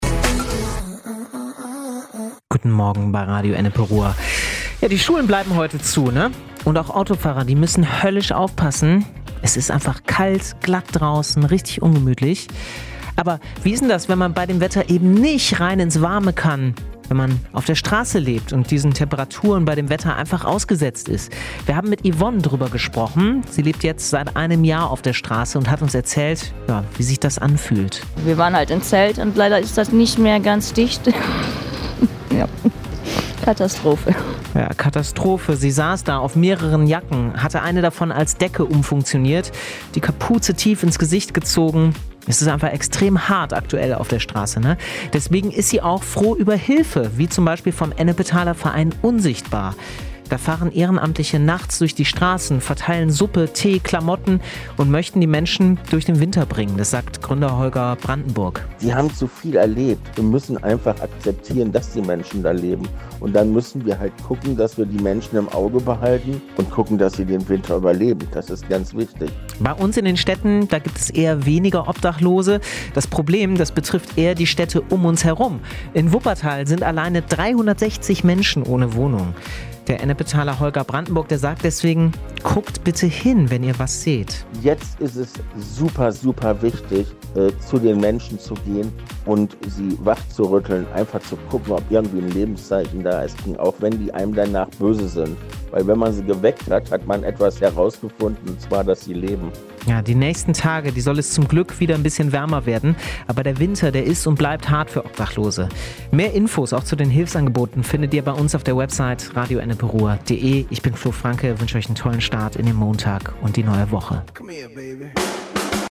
Wir haben mit einer Frau gesprochen, die seit einem Jahr auf der Straße lebt.